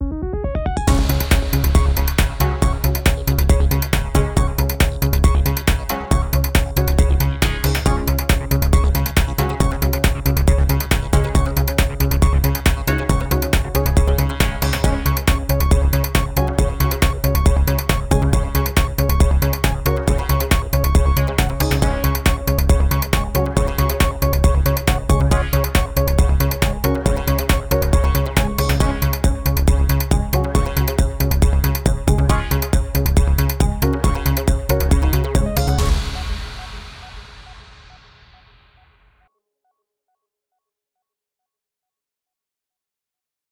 a short, high energy, 138 BPM news bed sounding thing in C sharp major.
No News Is Good News. This is an attempt at making some kind of news bed type thing.